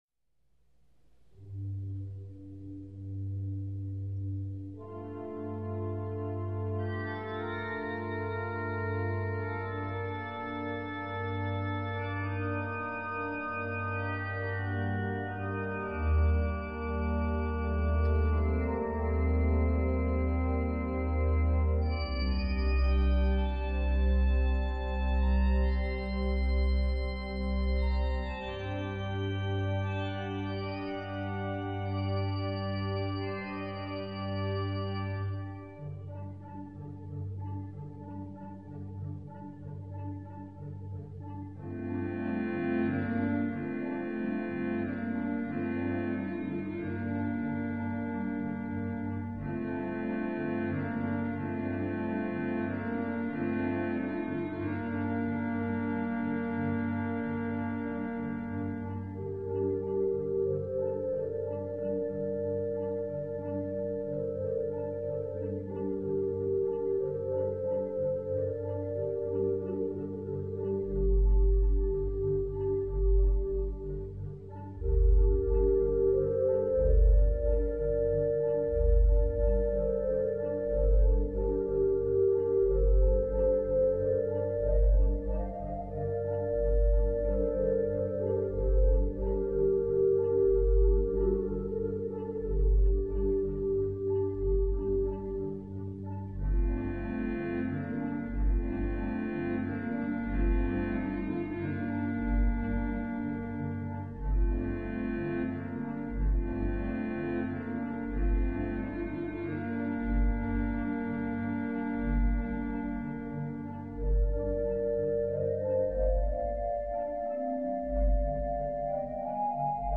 Preludes to worship at the First Church of Christ, Scientist, Brunswick, Maine
on the Allen Organ with orchestral synthesizer at First Church of Christ, Scientist, Brunswick Maine.